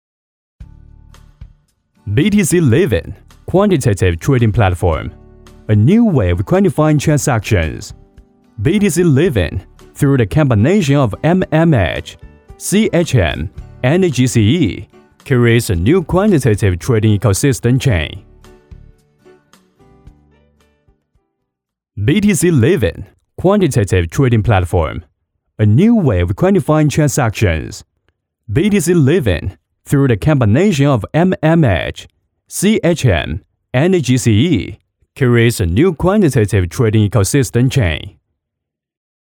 配音员：葡萄牙语女3
配音风格： 轻快